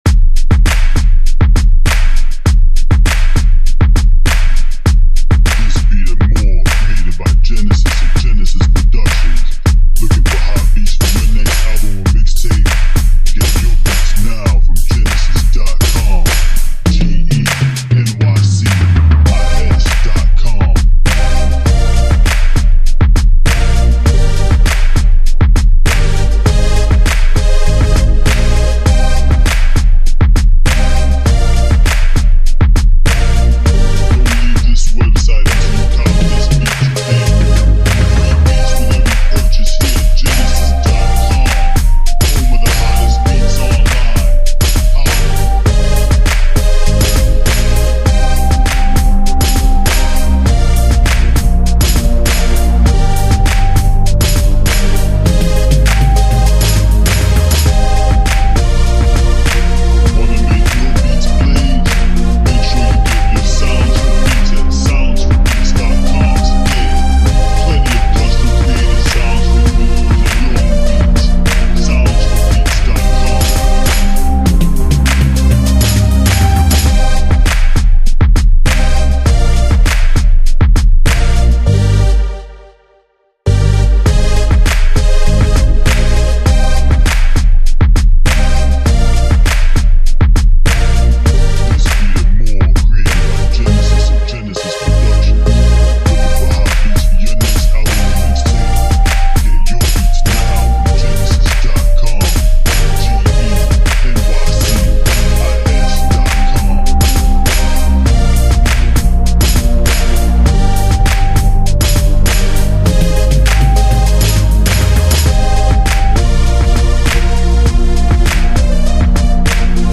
Pop / Club Beat